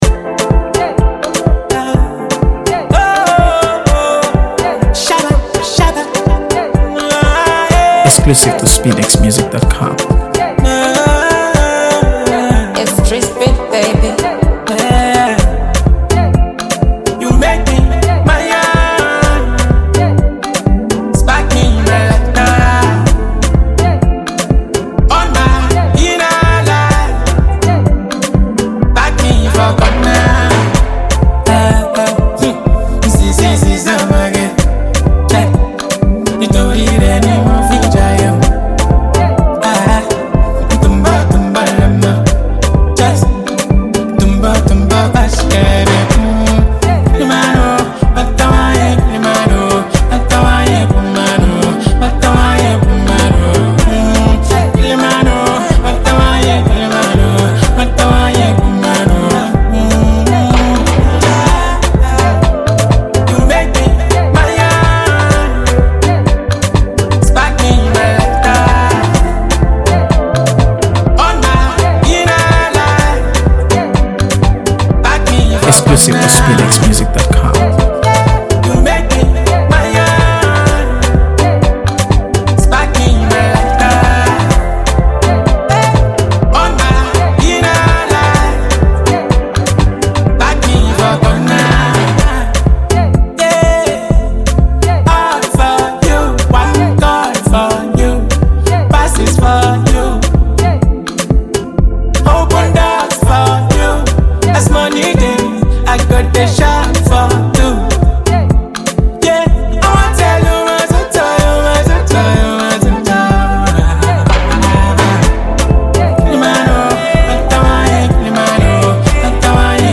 AfroBeats | AfroBeats songs
smooth vocals layered over a clean, mid-tempo instrumental